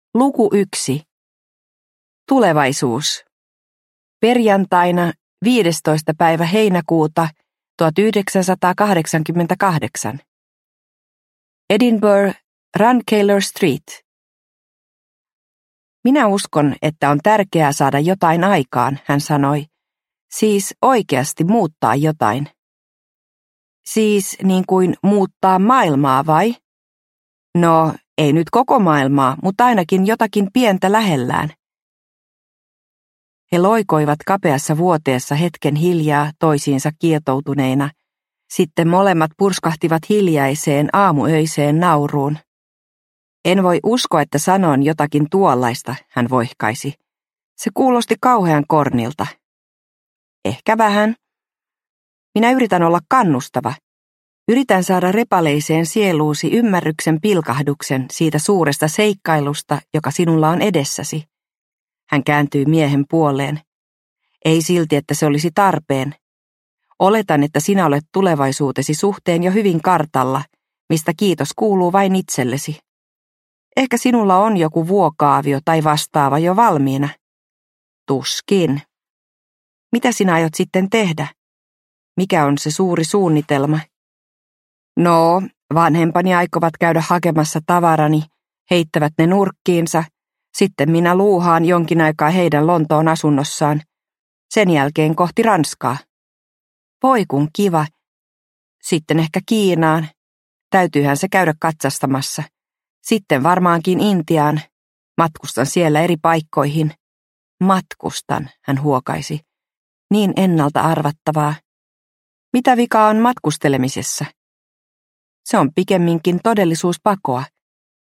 Sinä päivänä – Ljudbok – Laddas ner